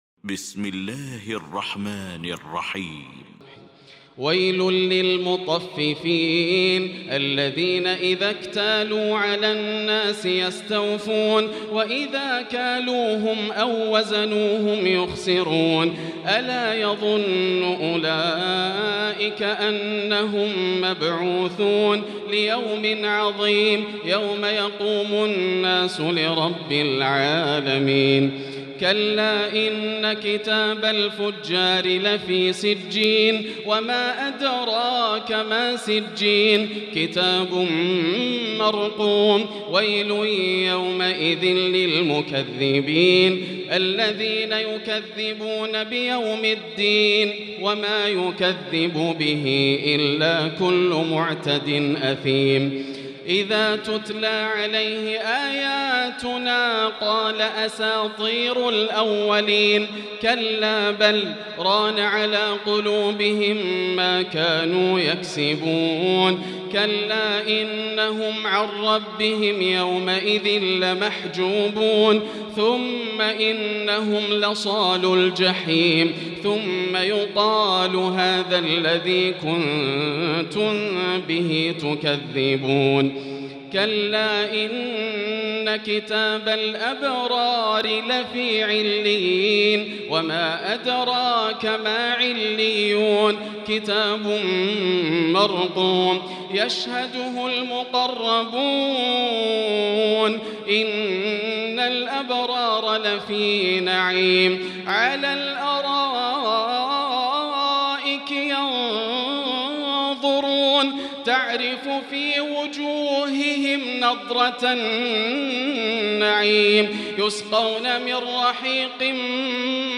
المكان: المسجد الحرام الشيخ: فضيلة الشيخ ياسر الدوسري فضيلة الشيخ ياسر الدوسري المطففين